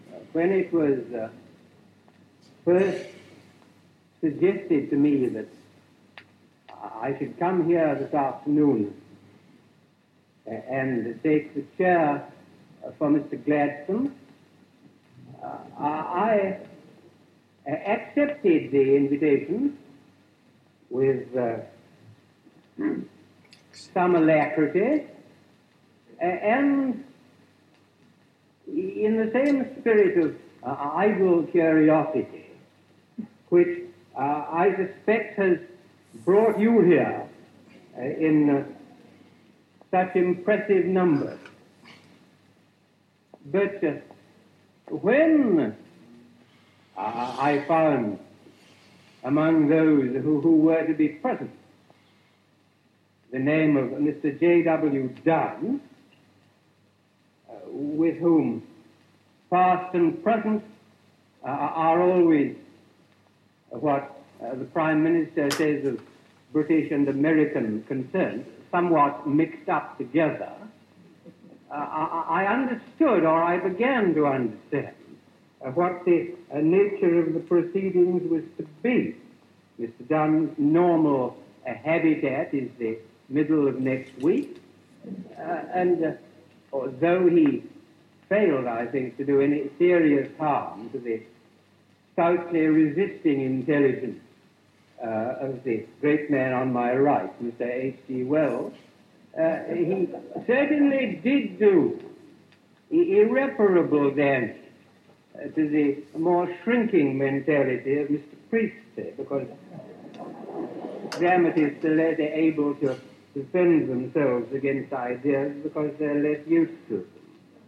This speaker shows (maybe consciously stylistic) slight stammering particularly on the word I. This is not transcribed in the phonemic transcription.